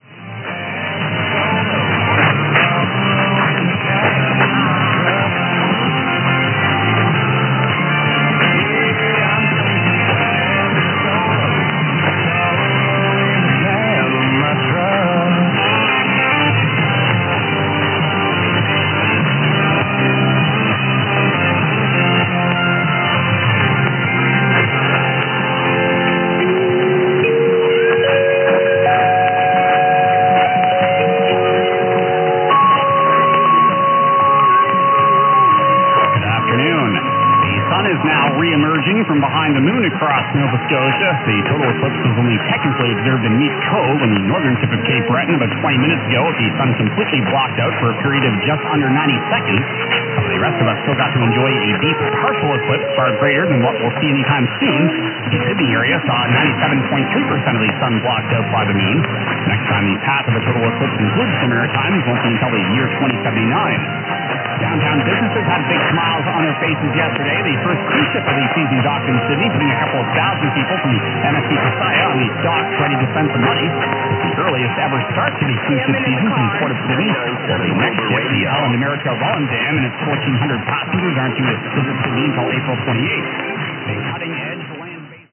Both SDR recordings were unattended and scheduled to run between 13:30 and 17:30 EDT.
Funny to hear on some of the recordings, a live coverage of the eclipse.